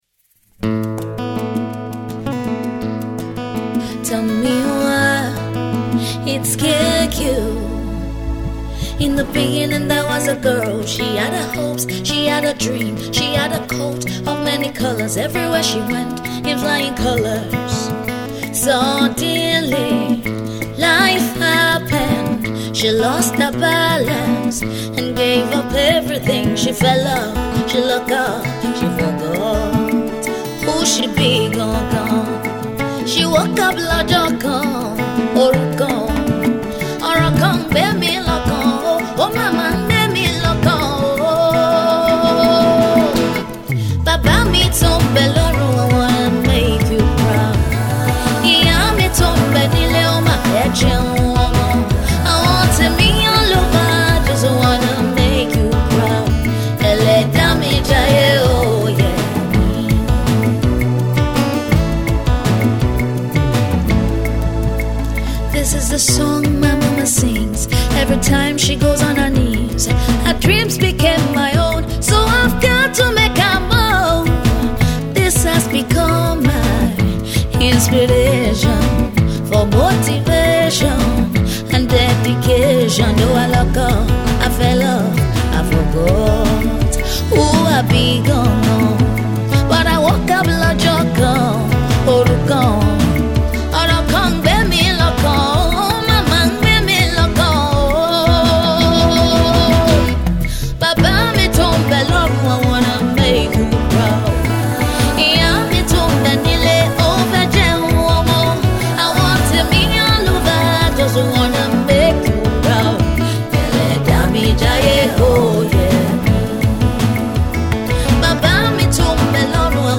Soulful Singer